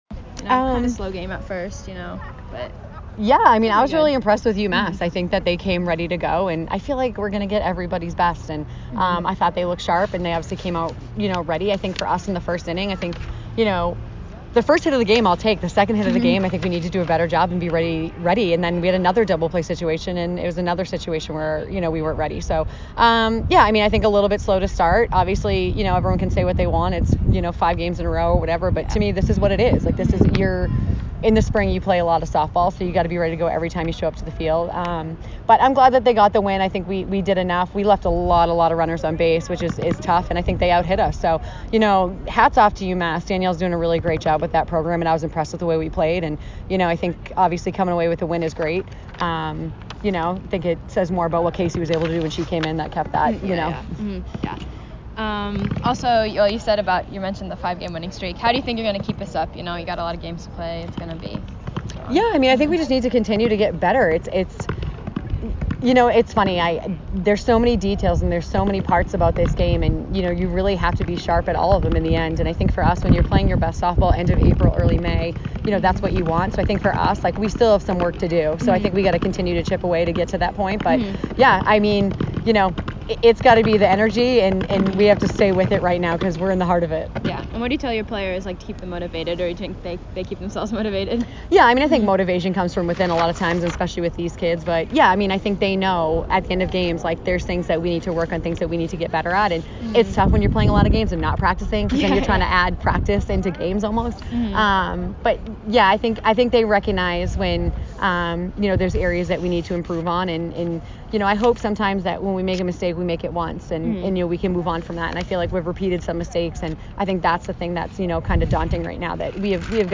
UMass Postgame Interview